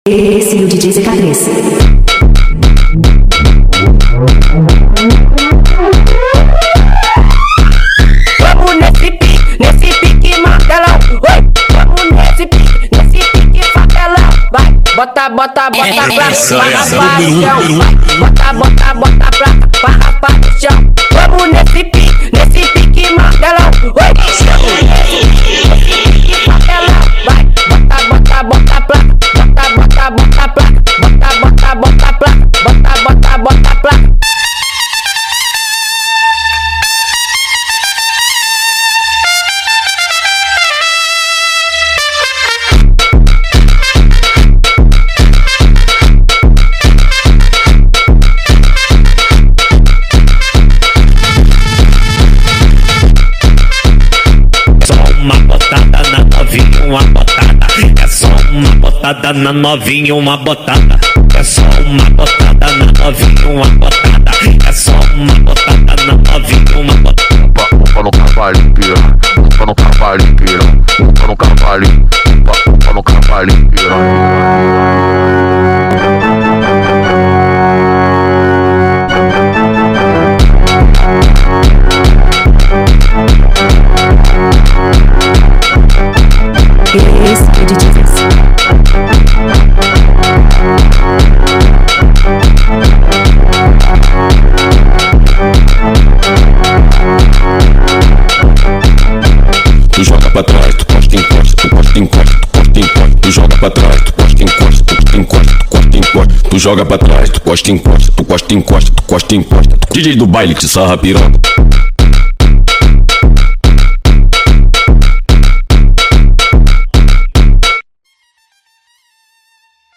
Phonk Ronaldinho